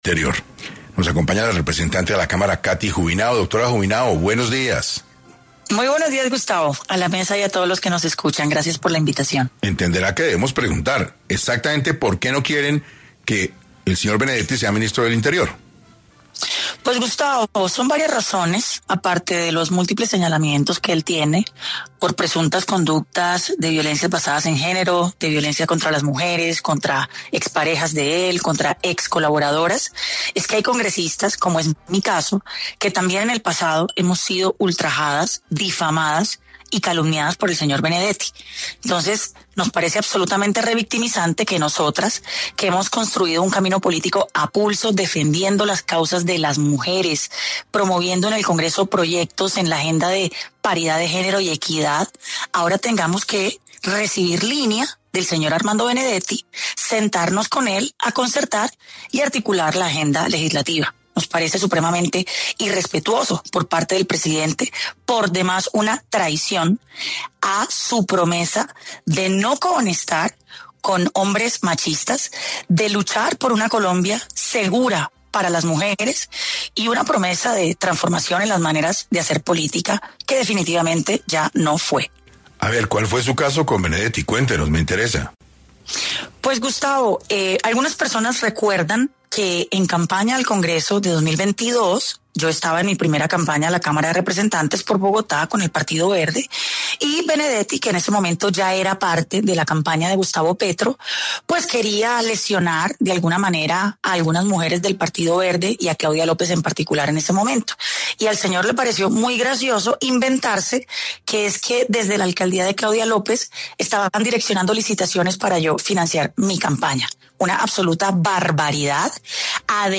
En 6AM Cathy Juvinao, representante a la cámara habló sobre lo que opinan las congresistas del nuevo cargo asignado por el presidente Gustavo Petro a Armando Benedetti.